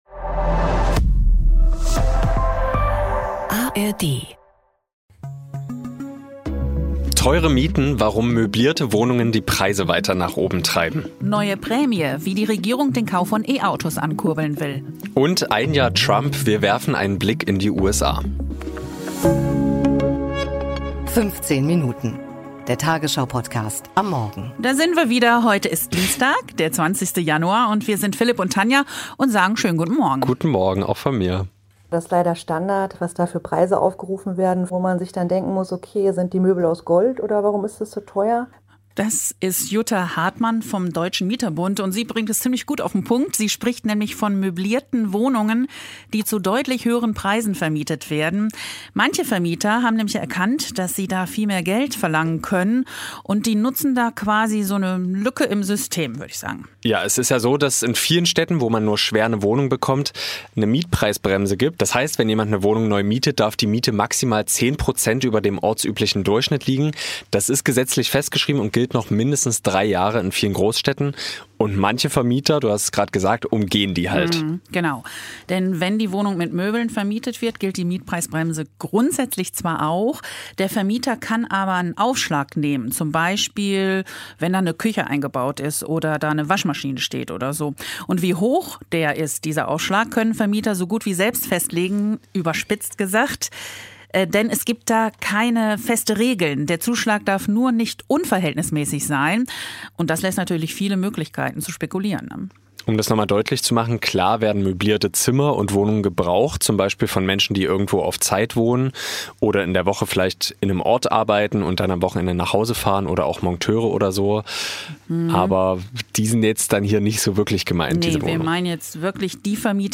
Eine deutsche Hörerin von 15 Minuten erzählt, wie sie das Land unter Trump in South Carolina erlebt.